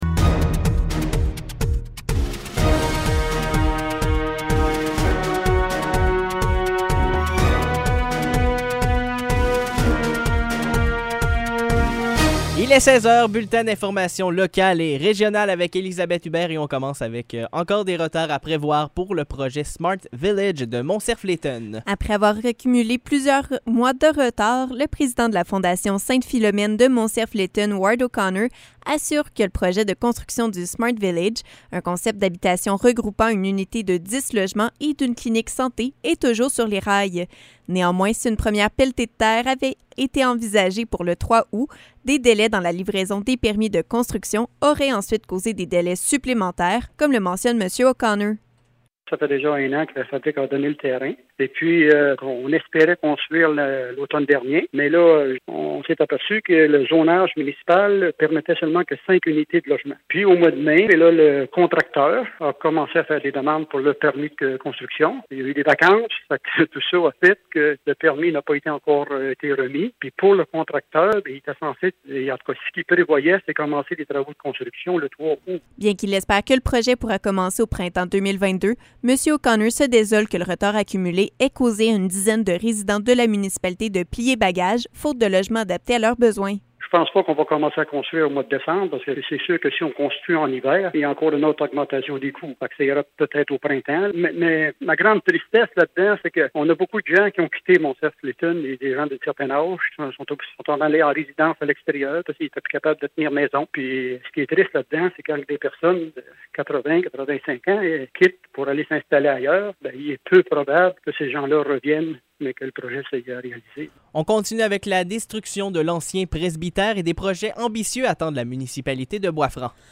Nouvelles locales - 24 août 2021 - 16 h